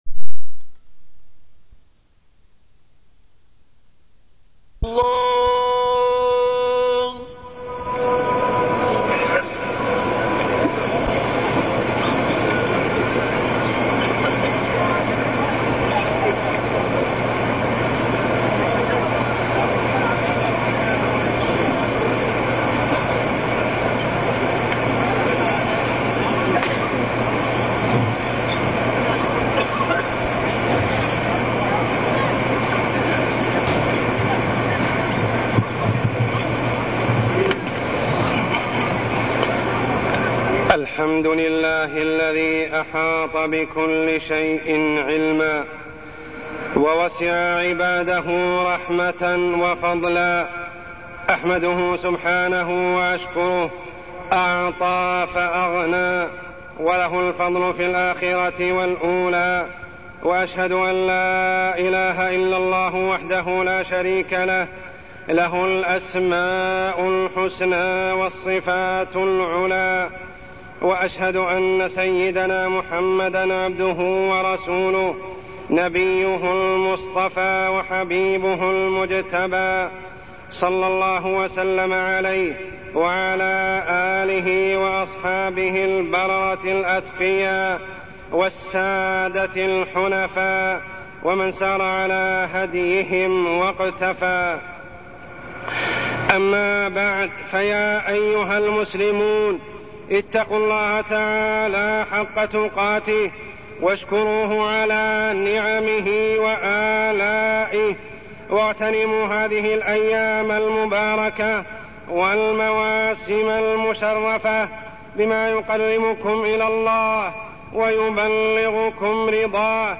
تاريخ النشر ١٦ رمضان ١٤٢٠ هـ المكان: المسجد الحرام الشيخ: عمر السبيل عمر السبيل العشر الأواخر من رمضان